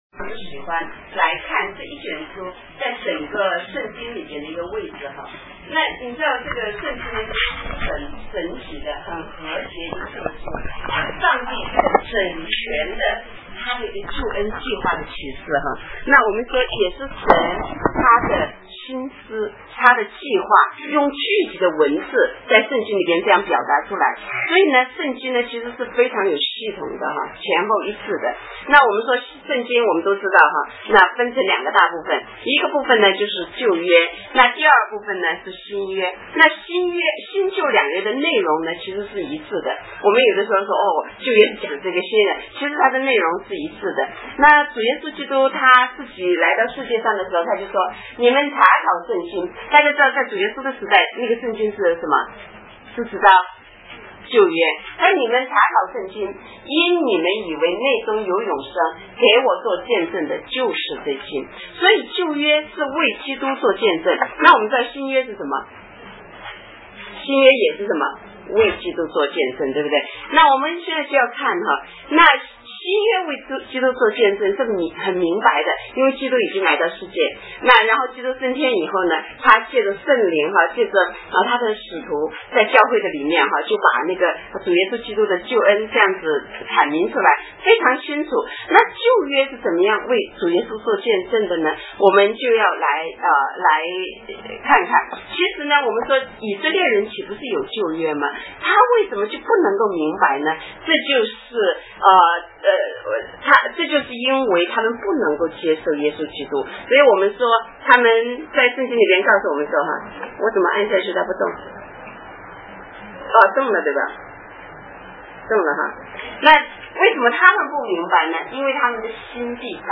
三谷基督徒會堂 成人主日學 利未記 Chinese Church in Christ Adult Sunday School Leviticus